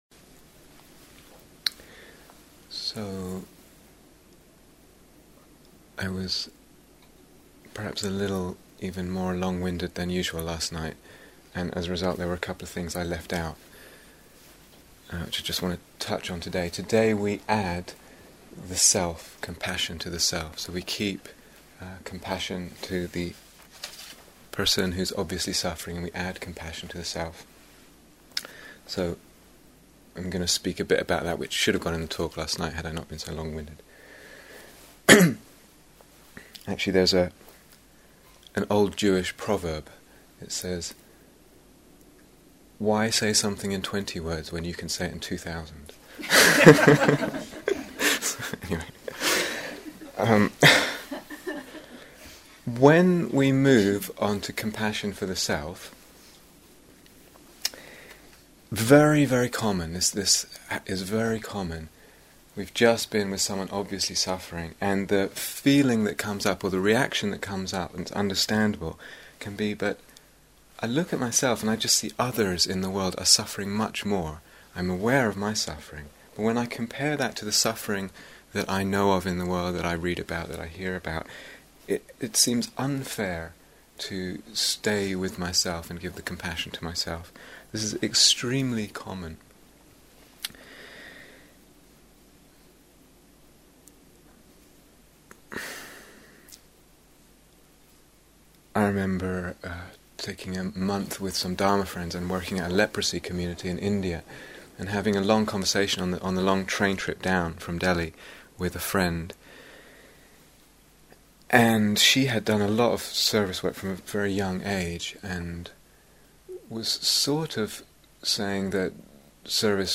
Here is the full retreat on Dharma Seed This series of talks and guided meditations explores the development of the practices of both Lovingkindness and Compassion, with particular emphasis on the radical possibilities of Awakening that they bring.